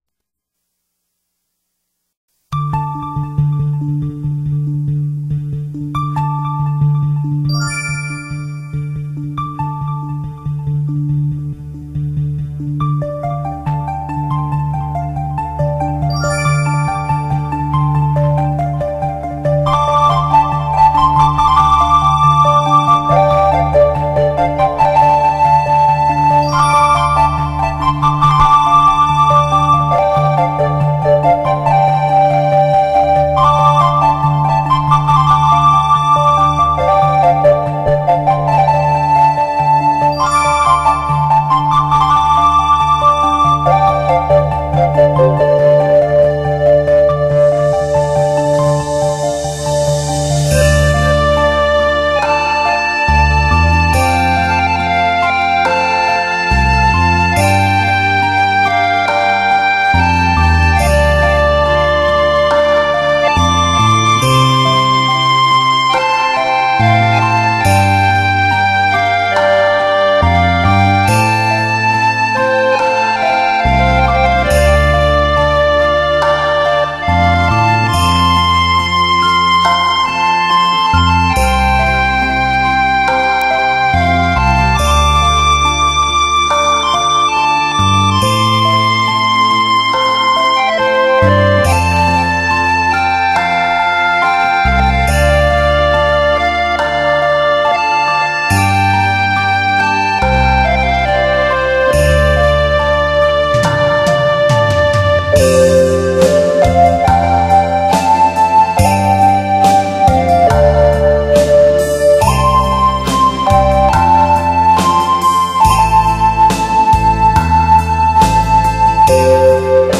中国佛教音乐是中国音乐文化的重要组成部分，是中华民族的宝贵文化遗产。
其音清新典雅，超凡脱俗，其韵幽远深长，唱者身心合一，物我两忘；
小提琴与笛子、琵琶